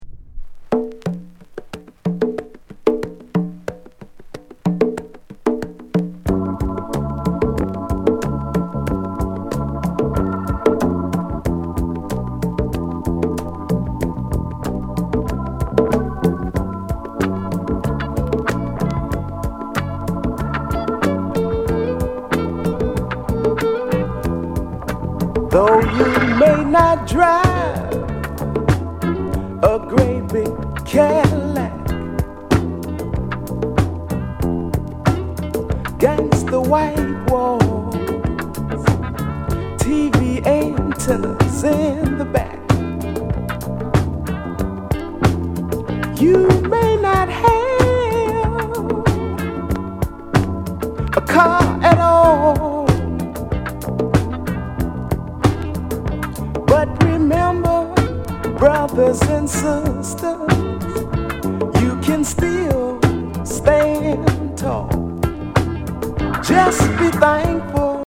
SOUL CLASSICS